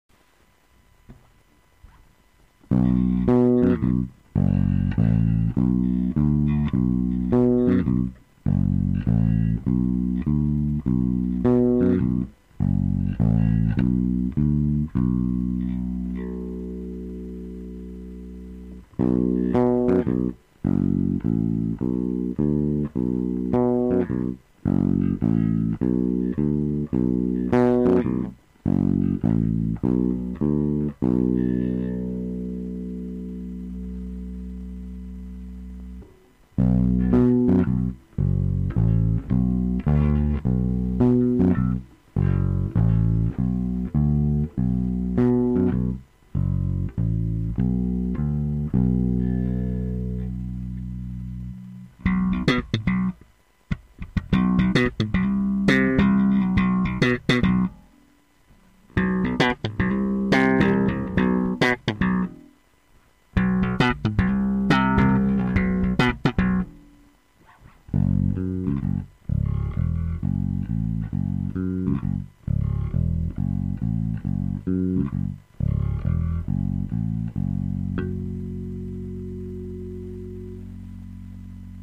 po 5 hodinách učení bez metronomu do notce skrz hlavu, fakt mi to nejde udělat líp, ale jen pro takovou malou představu, šumí chrčí a vrčí to, a kupodivu to v reálu hraje naprosto jinak big_smile
Skvér vm V špatná kvalita